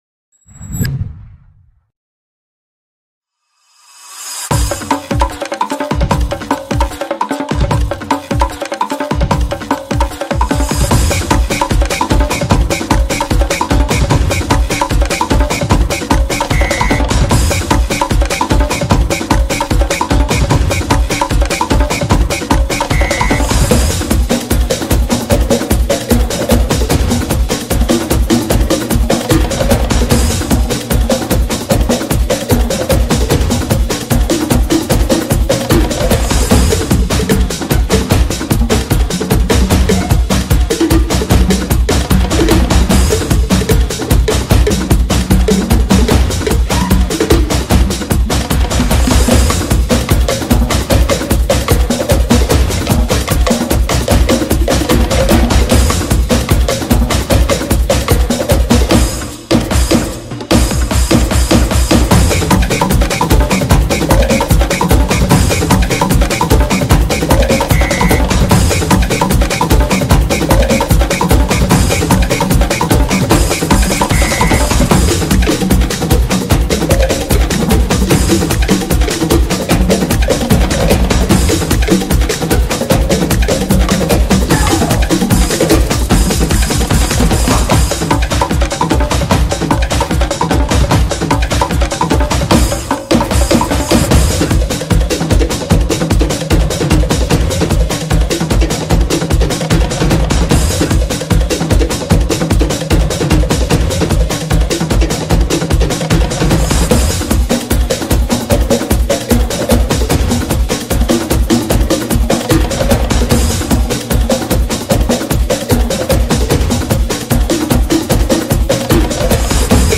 fiery drumming style and Latin rhythms